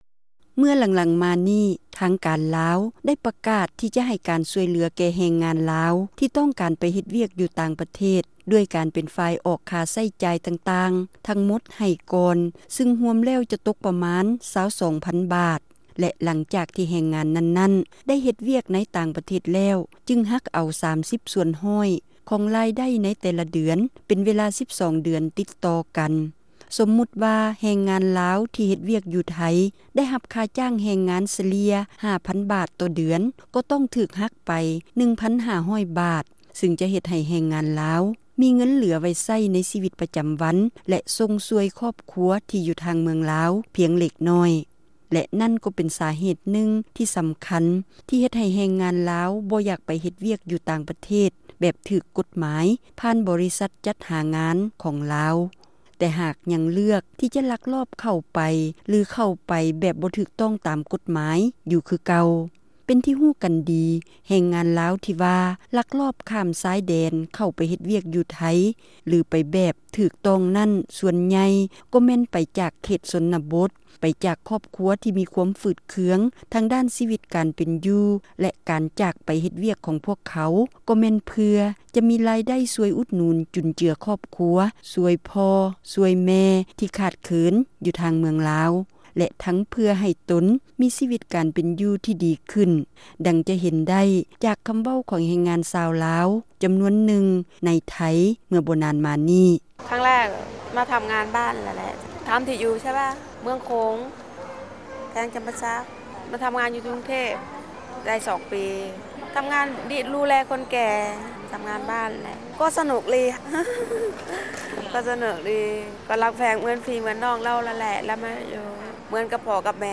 ຣາຍການໜໍລຳ ປະຈຳສັປະດາ ວັນທີ 30 ເດືອນ ເມສາ ປີ 2007